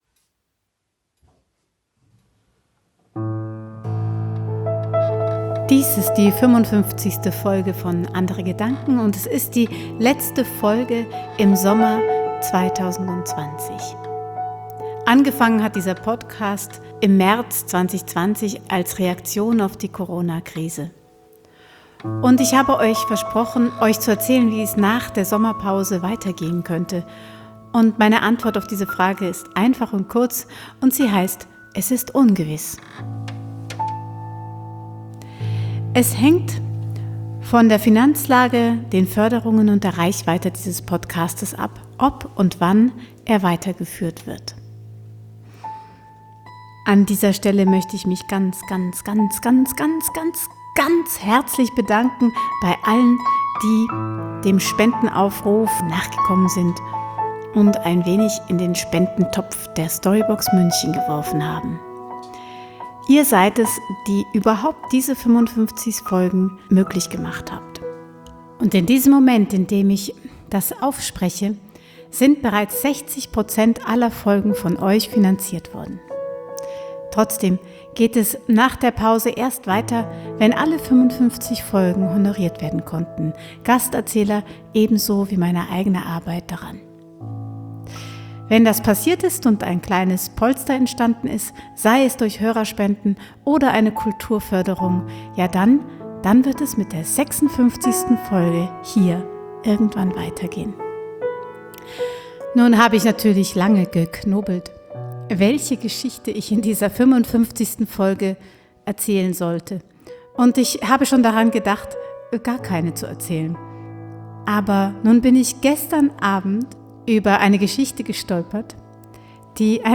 frei erzählte Geschichten, Musik, Inspiration